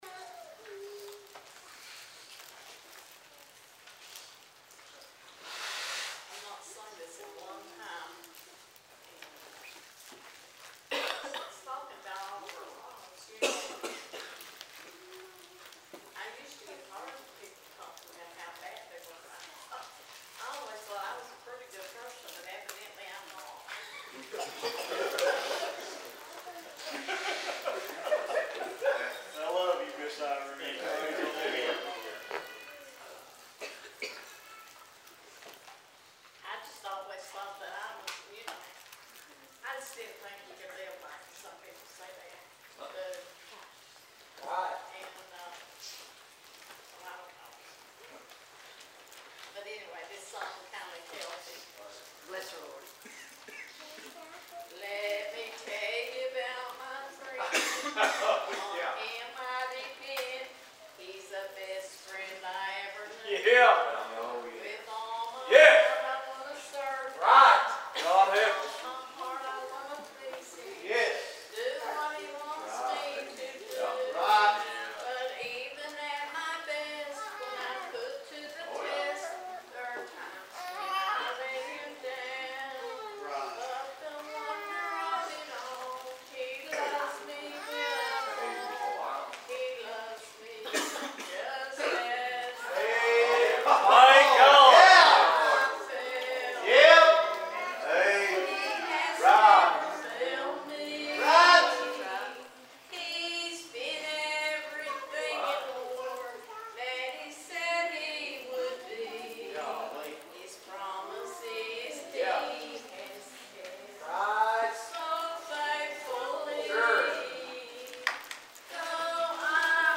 Ephesians 4:1-6 Service Type: Sunday Evening « February 25